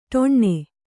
♪ ṭoṇṇe